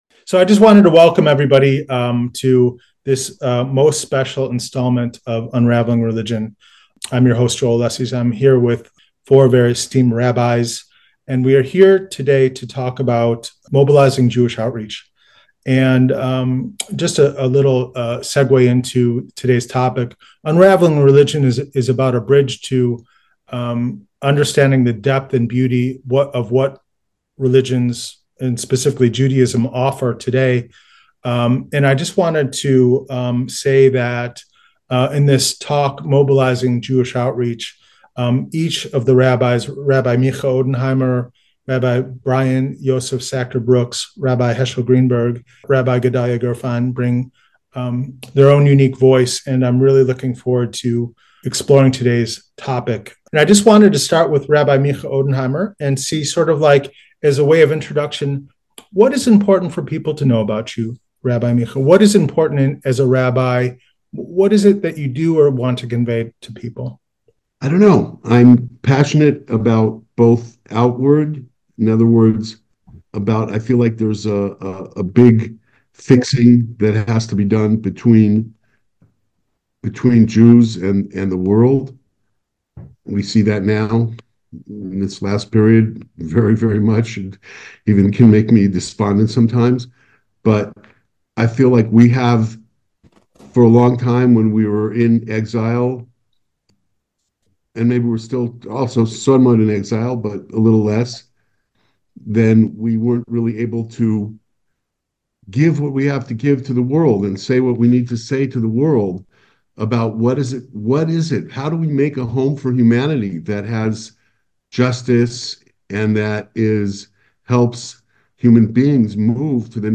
Mobilizing Jewish Outreach, Bringing A Person Closer To Wisdom: A Roundtable Discussion Of Rabbis Exploring Strengthening Jewish Commitment